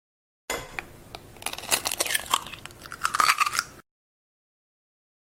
Tiếng Đập và Bóc vỏ trứng (1 lần)
Thể loại: Tiếng ăn uống
Âm thanh bắt đầu bằng tiếng đập nhẹ hoặc mạnh vào bề mặt, theo sau là tiếng vỡ lốp của vỏ trứng, rồi đến âm thanh lột vỏ giòn giòn, lách tách.
tieng-dap-va-boc-vo-trung-1-lan-www_tiengdong_com.mp3